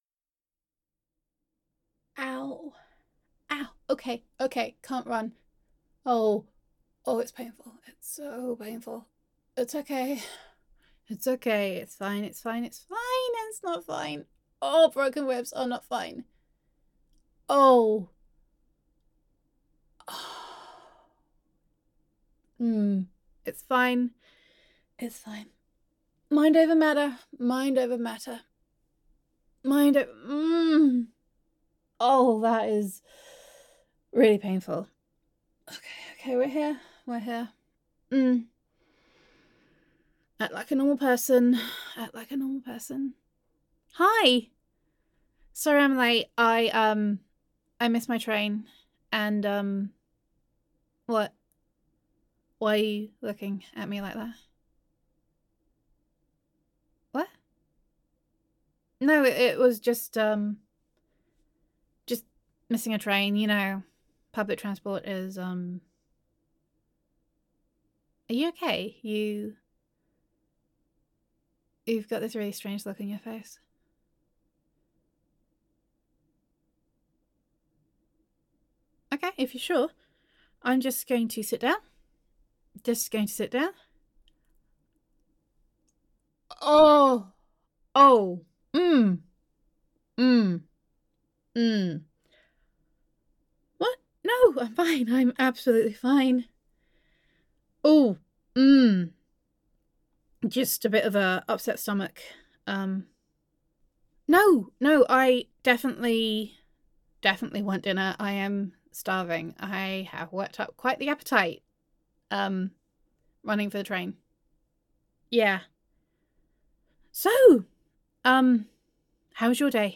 [F4A] Overprotective [Best Friend Roleplay][No Superpowers][Love Confession][Friends to Lovers][Broken Ribs][Pokey Fingers of Pain][Ow][Owwwwwwwwww][Gender Neutral][You Discover That Your Best Friend Is a Masked Vigilante]